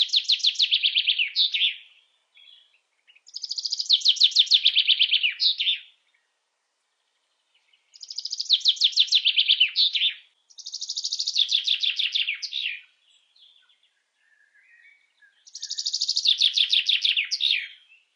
燕雀鸟叫声